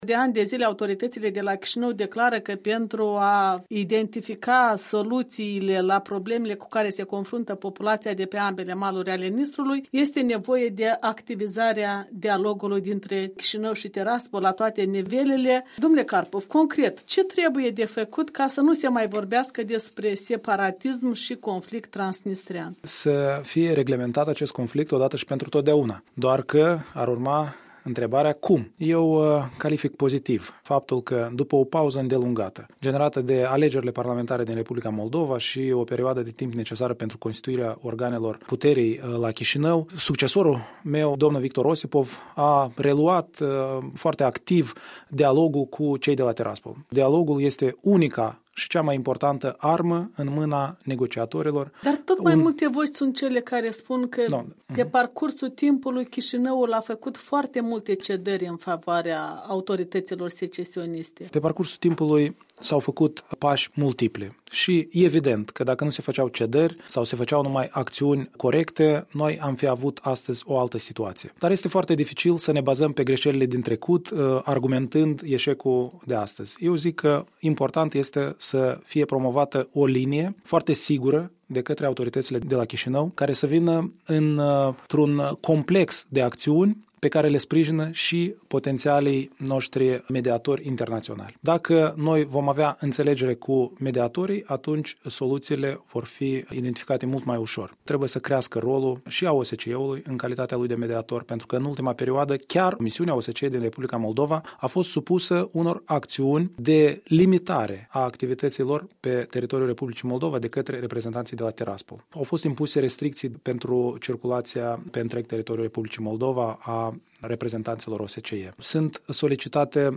Un interviu în exclsuivitate pe tema chestiunii transnistrene cu deputatul Eugen Carpov